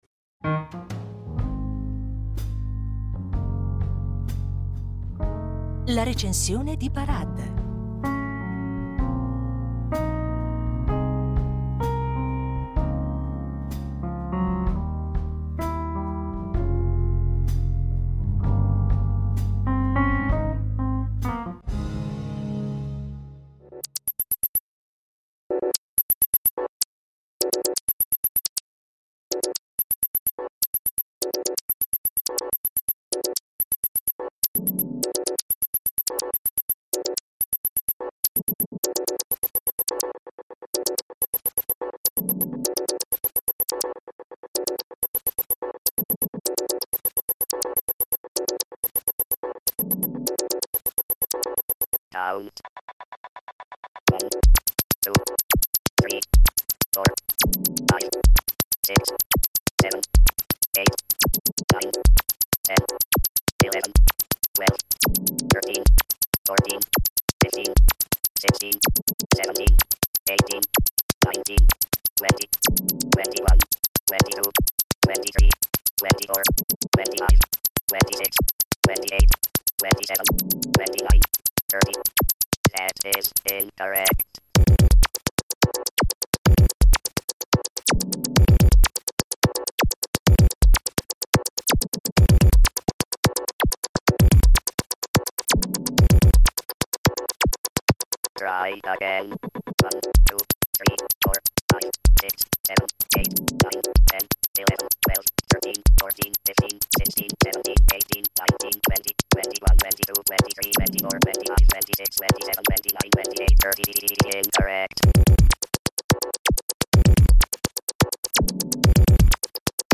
La Recensione
Il genere della “glitch music” nasce, come molte invenzioni, dal caso. Abbiamo presenti quei suoni distorti e ripetitivi che vengono fuori quando un CD è danneggiato?